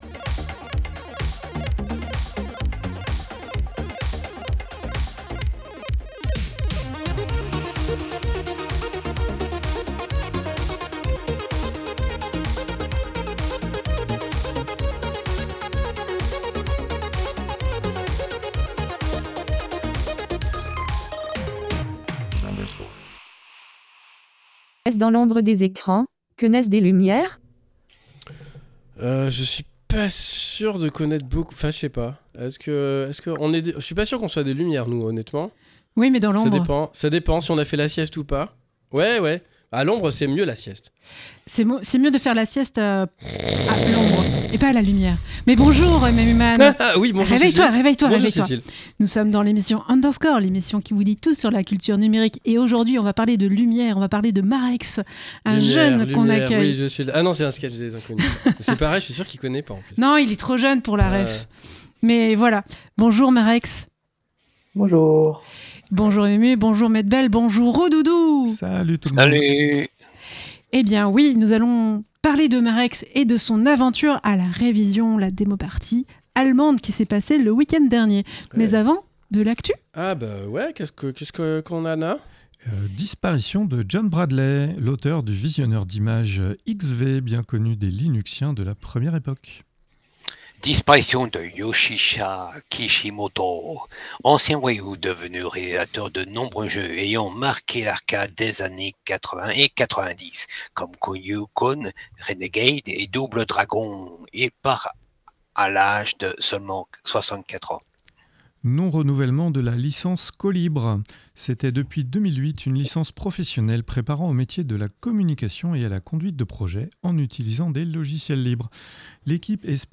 Actu